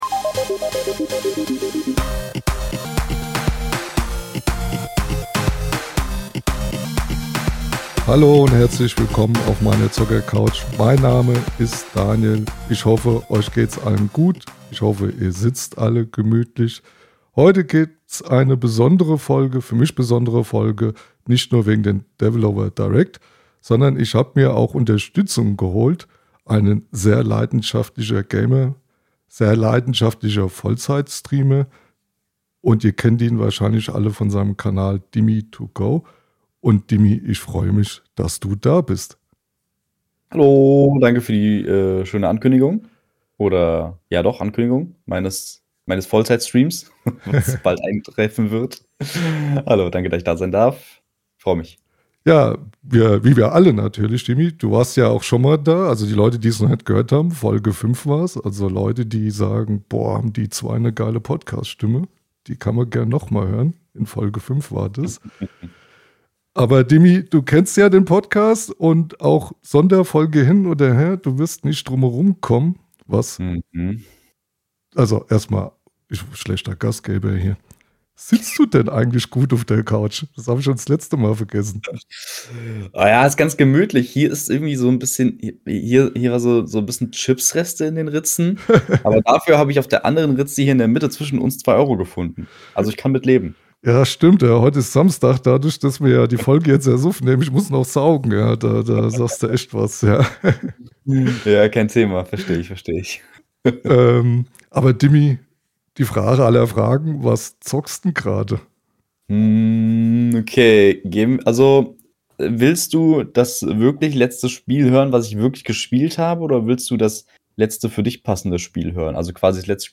Mach es dir gemütlich und hör rein – ein lockerer, entspannter Talk für alle Xbox-Fans!